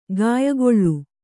♪ gāyagoḷḷu